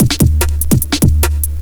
JUNGLE6-L.wav